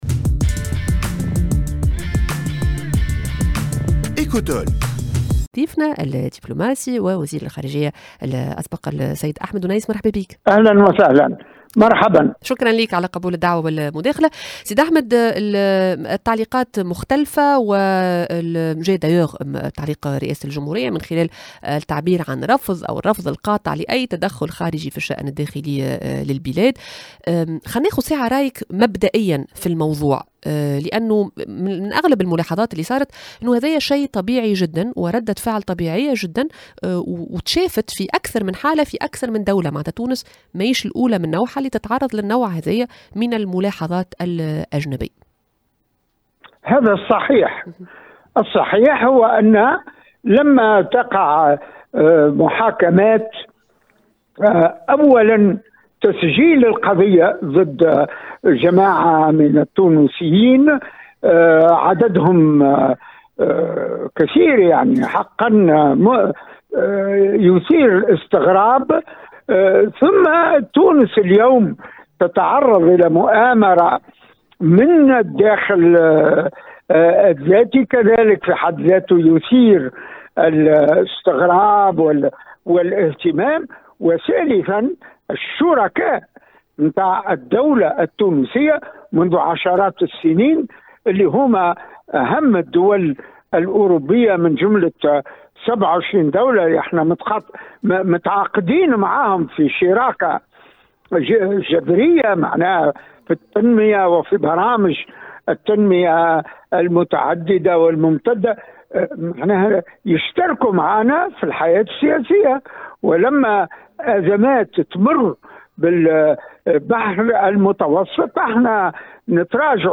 Les accusations d’ingérence dans les affaires nationales peuvent-elles impacter nos relations économiques? Le diplomate Ahmed Ounaies